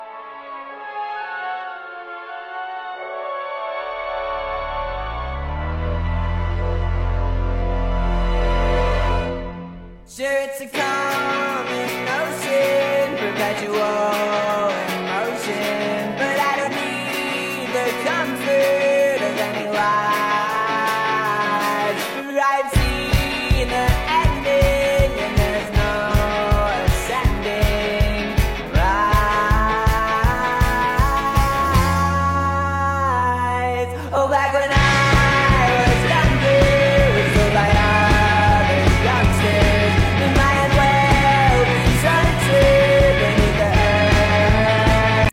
pro charged jeep srt8 engine sound effects free download